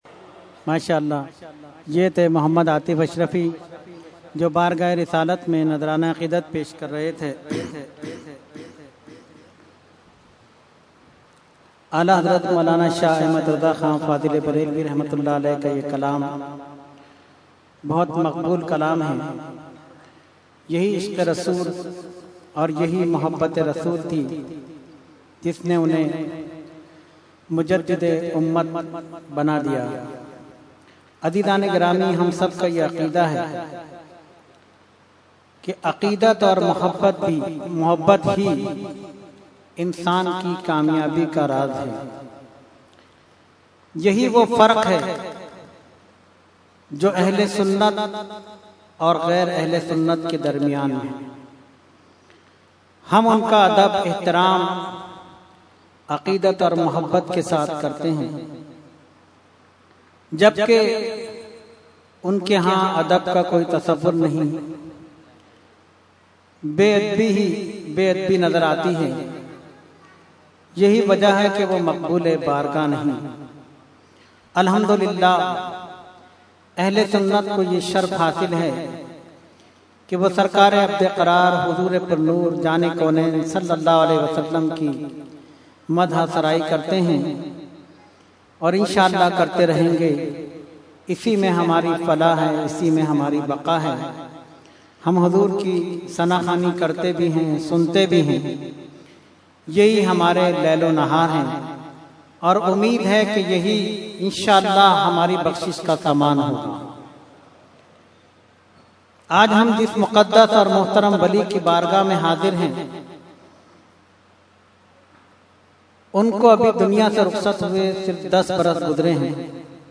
Category : Speech | Language : UrduEvent : Urs Ashraful Mashaikh 2015